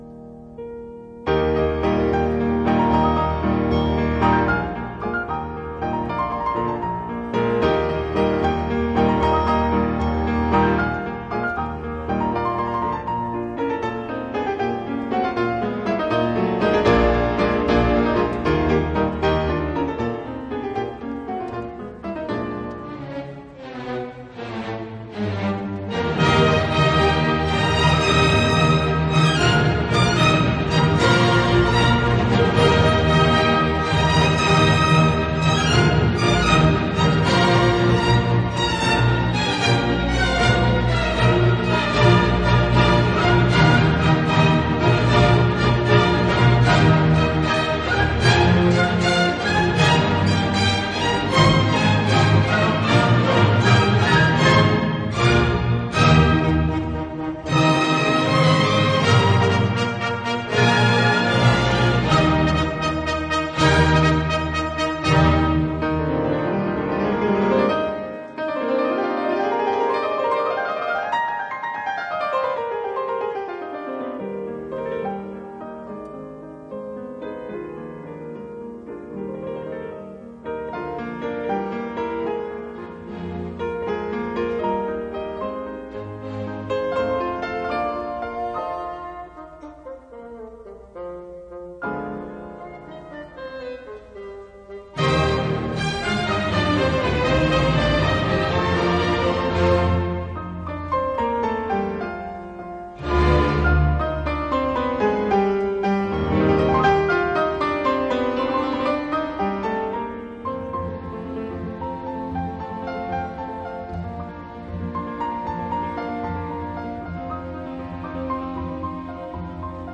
Recording: Manhattan Center, New York City, USA